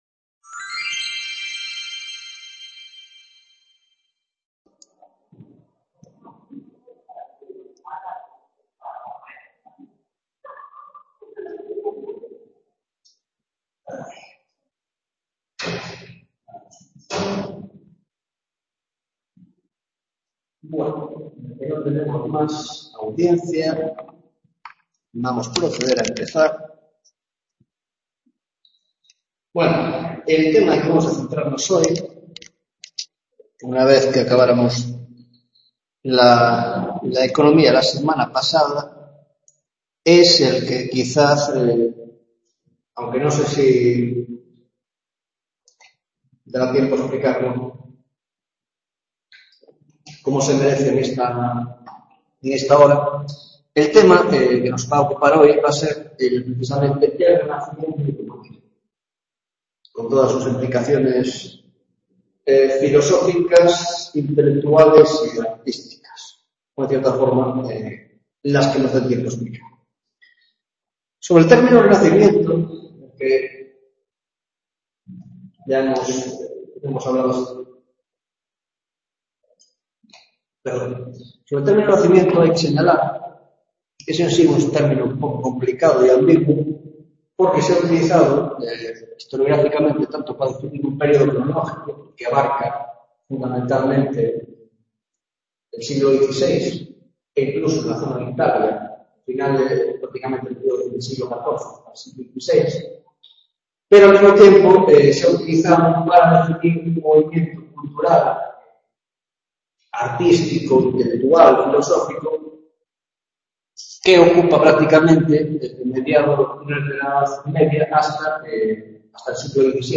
Tutoria de Historia Moderna